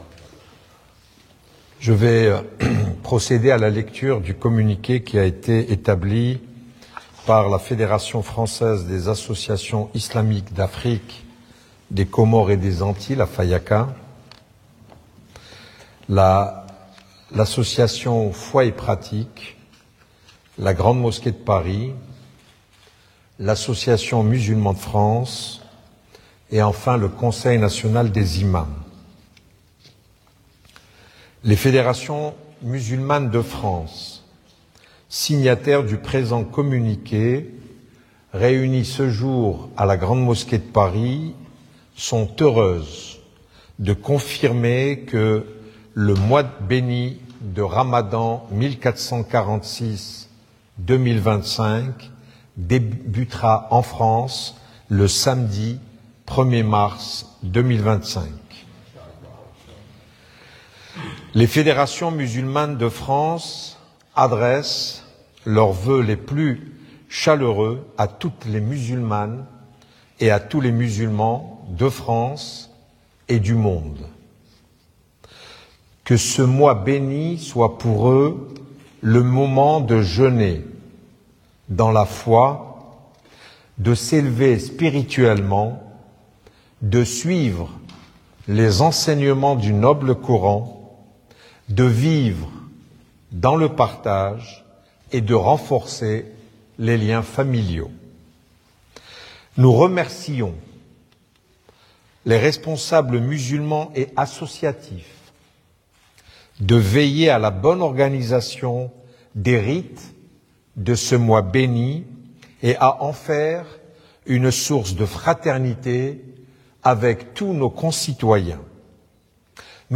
RO Début du ramadan Le ramadan commence samedi 1er mars : annonce du Recteur de la Grande Mosquée de Paris, Chems-Eddine Hafiz. L'annonce a été faite en direct sur Radio Orient et sur nos réseaux sociaux. 0:00 4 min 49 sec